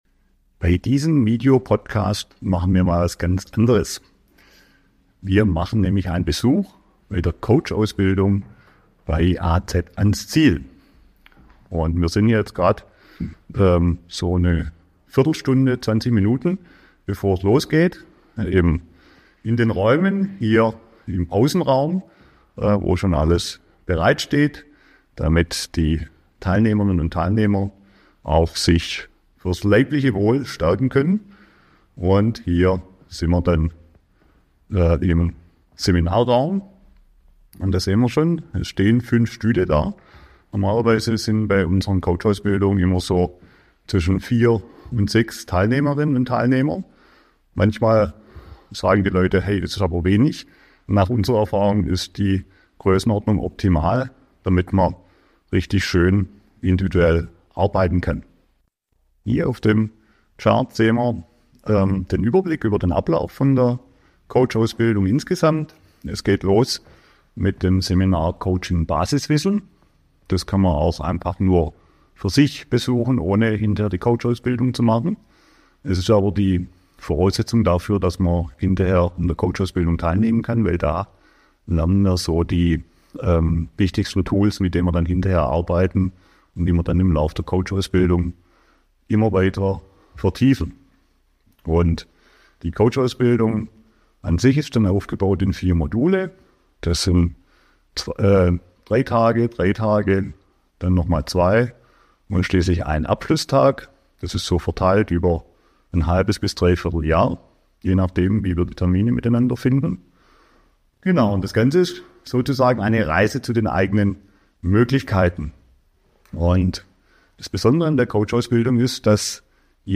Kulissen – direkt in den Seminarraum nach Überlingen am Bodensee.